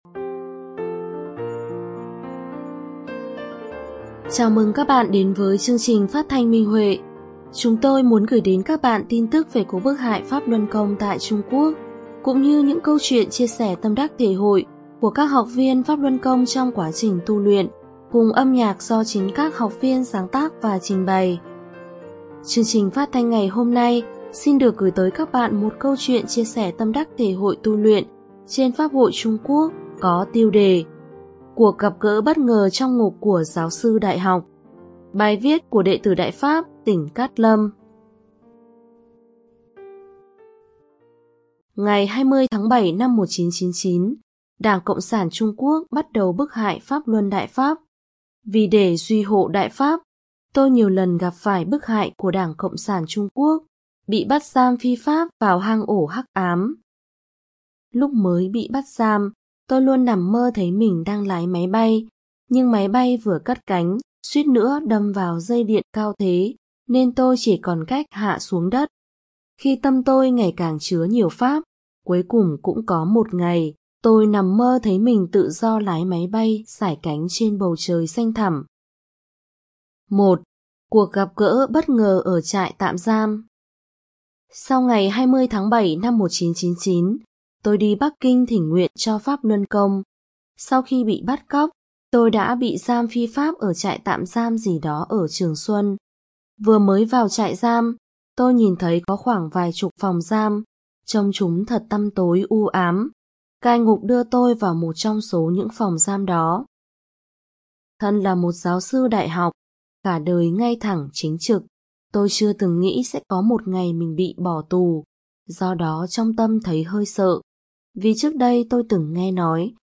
Chào mừng các bạn đến với chương trình phát thanh Minh Huệ. Chúng tôi muốn gửi đến các bạn tin tức về cuộc bức hại PhápLuân Công tại Trung Quốc cũng như những câu chuyện chia sẻ tâm đắc thể hội của các học viên trong quá trình tu luyện, cùng âm nhạc do chính các học viên sáng tác và trình bày.